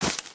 Divergent / mods / Footsies / gamedata / sounds / material / human / step / t_bush3.ogg